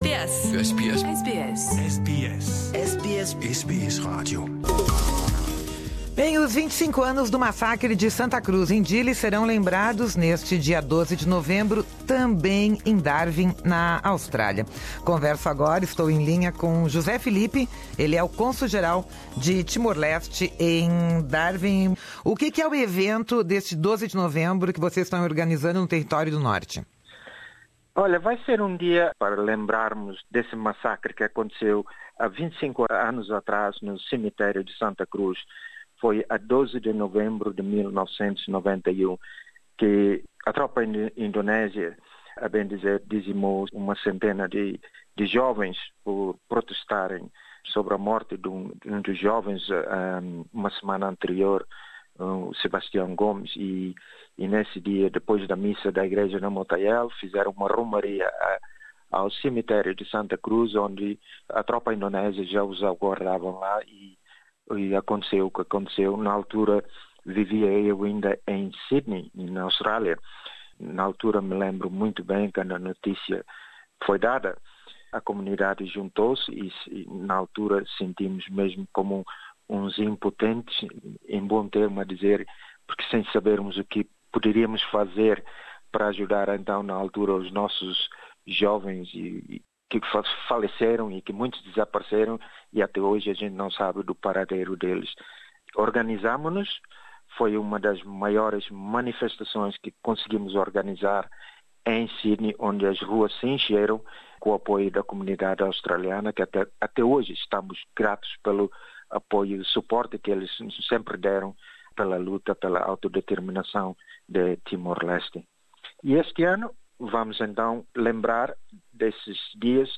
Nersta entrevista à Rádio SBS, o Cônsul José Felipe tamnbém pede aos timorenses que procurem o seu consulado mais próximo para fazerem o seu registro, para poder voltar nas eleições gerais do país, no ano que vem.